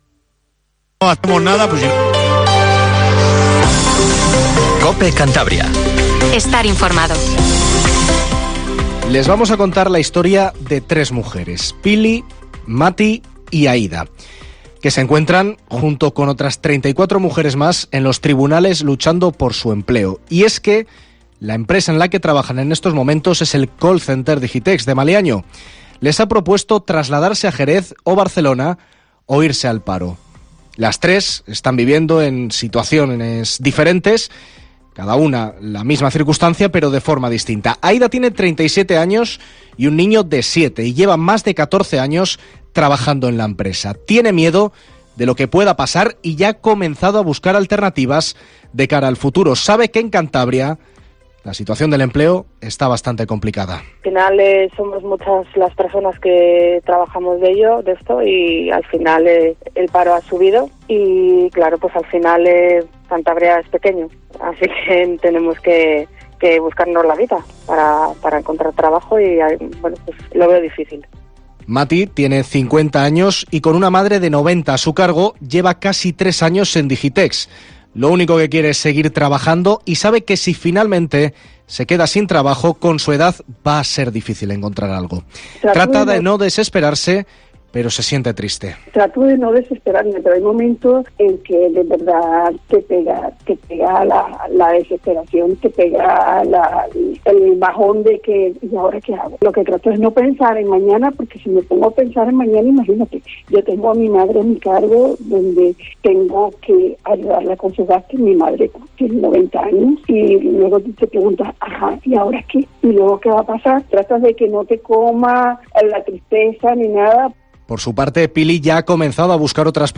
Informativo LA LINTERNA en COPE CANTABRIA 19:50